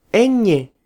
Ñ or ñ (Spanish: eñe [ˈeɲe]
Letter_ñ_es_es.flac.mp3